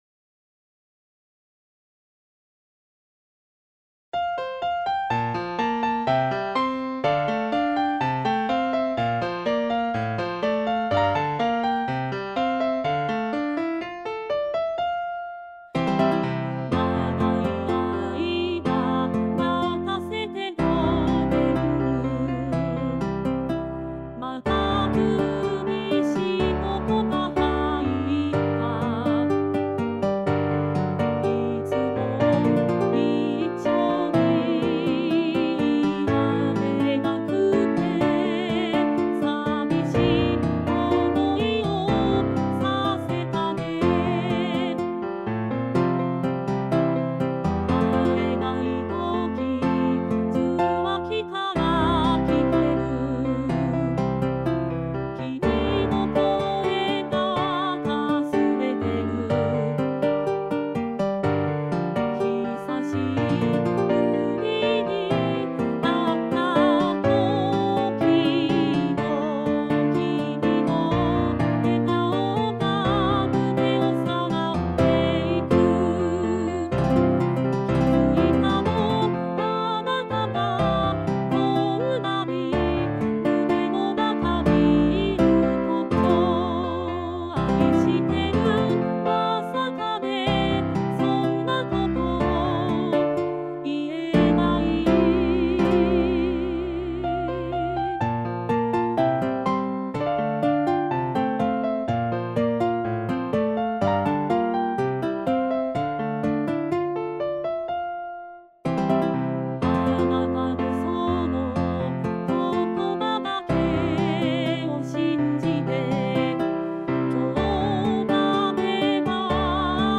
今回はこれを使って歌詞入力を行い、PCに歌わせてみました。
・音源：　KAWAIソフト音源